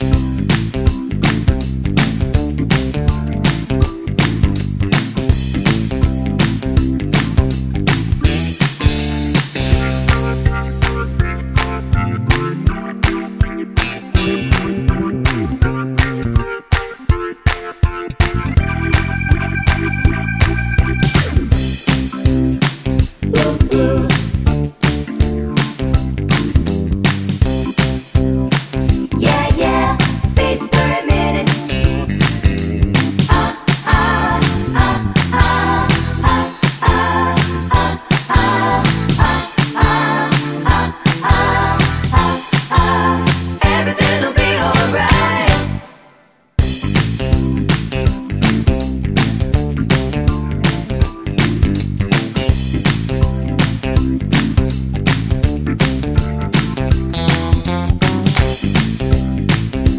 Medley Pop (1980s)